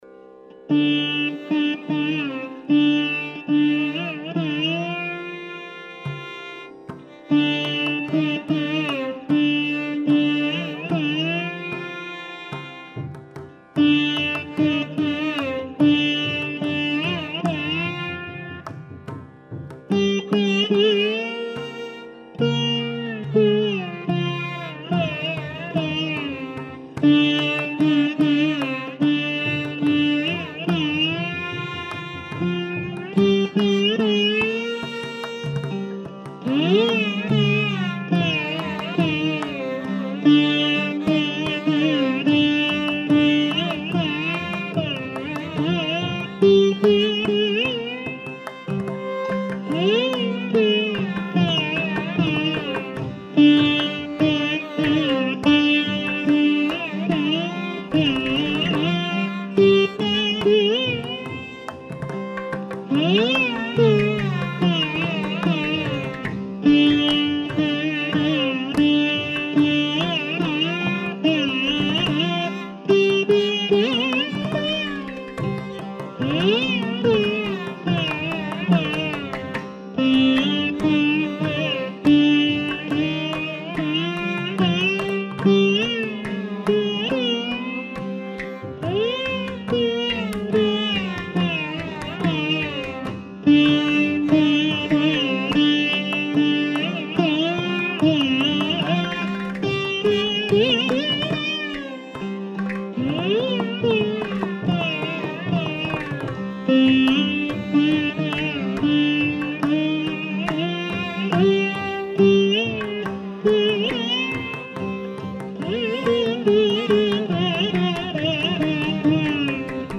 Carnatic: